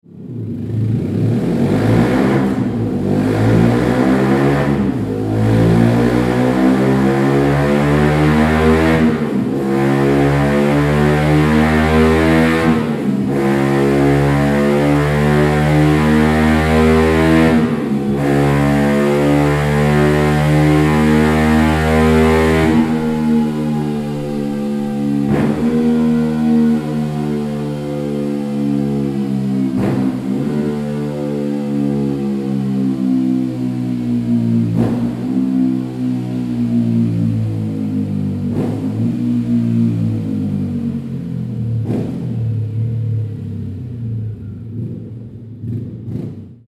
• Ligne complète avec silencieux finition Inox noir - Coupelle au choix : Alu noir ou Carbone